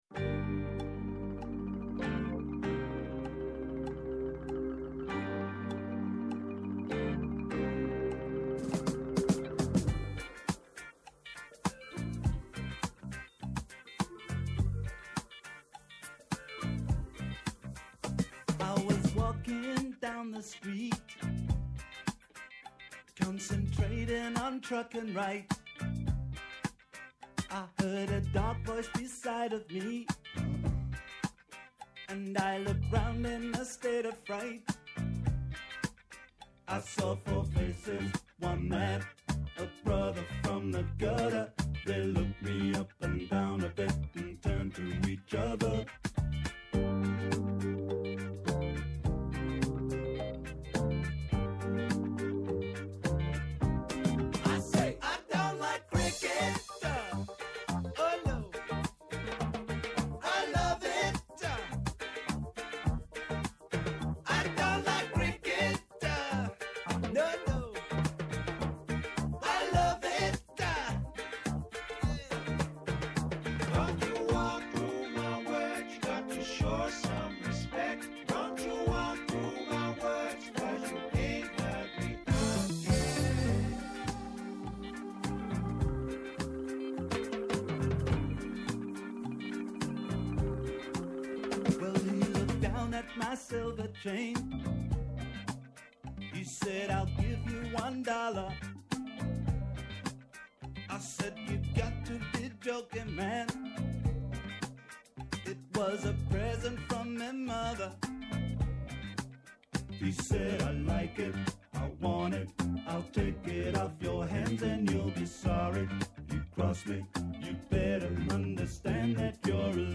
διεθνολόγος Η “Πρωινή Παρέα” με πολλές ώρες πτήσης στον ραδιοφωνικό αέρα είναι εδώ και κάθε μέρα 6 με 8 το πρωί, από Δευτέρα έως και Παρασκευή, σας κρατάει συντροφιά στο ξεκίνημα της ημέρας. Σας ξυπνάμε, σας ενημερώνουμε, σας διασκεδάζουμε και απαντάμε σε όλες σας τις απορίες.